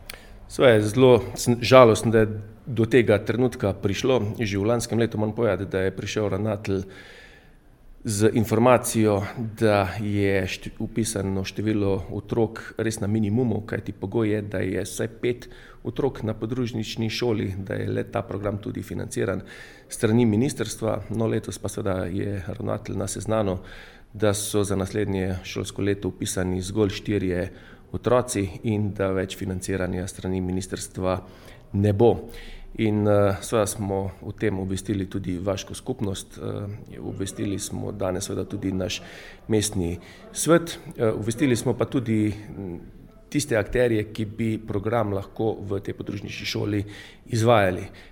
Župan odločitev za zaprtje podružnične šole v Šmiklavžu obžaluje:
izjava Klugler - Smiklavz na splet.mp3